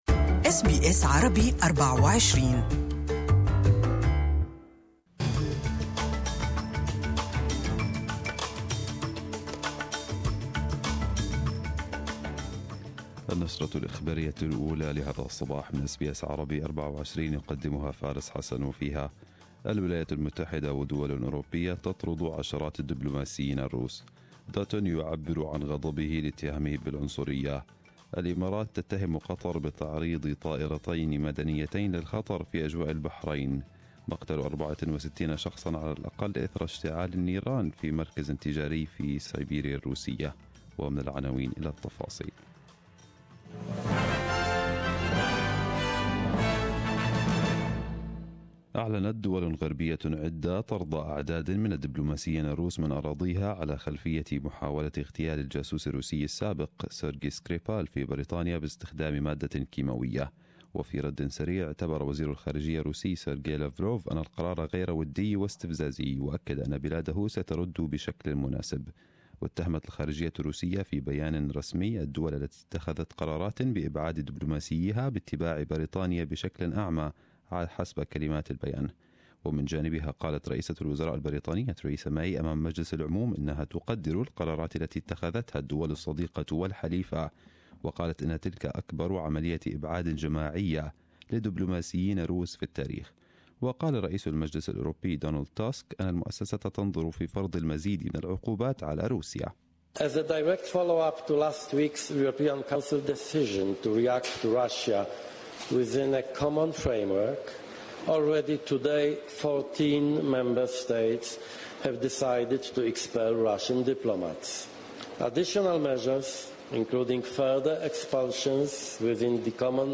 Arabic News Bulletin 27/03/2018